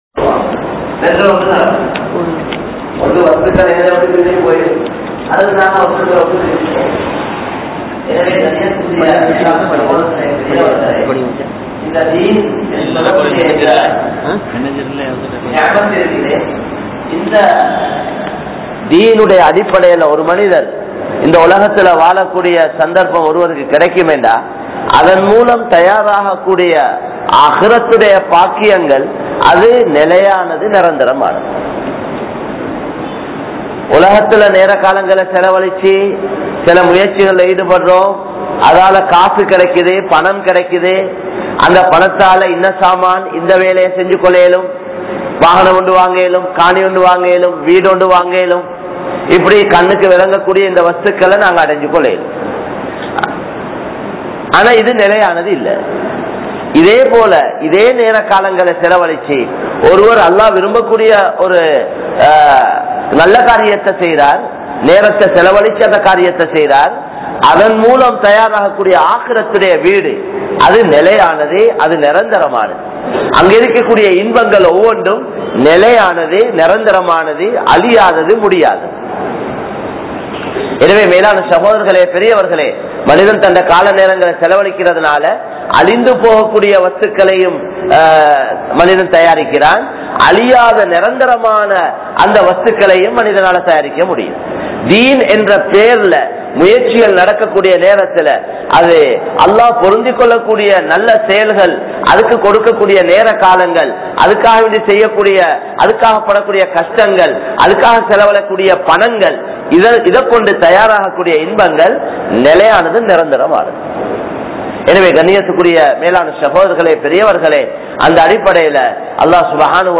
Allah`vinaal Safikka Pattavaihal (அல்லாஹ்வினால் சபிக்கப்பட்டவைகள்) | Audio Bayans | All Ceylon Muslim Youth Community | Addalaichenai
Kollupitty Jumua Masjith